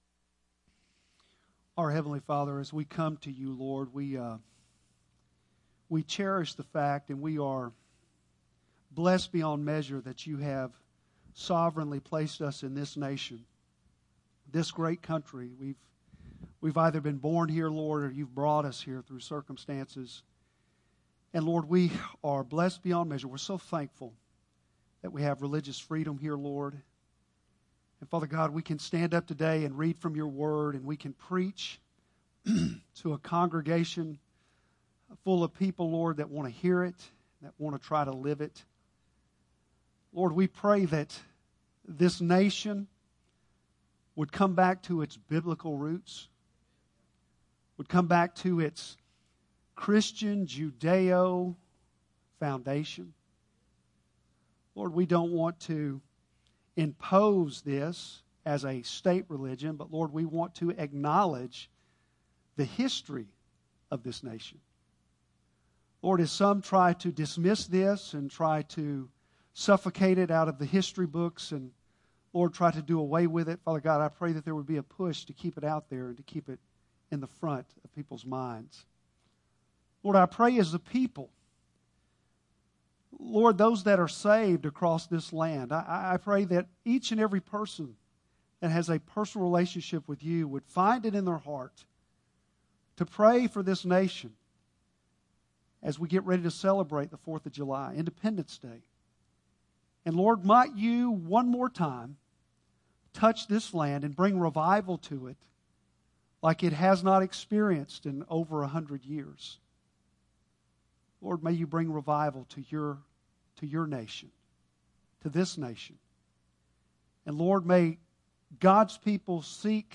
Bible Text: Ephesians 6:4 | Preacher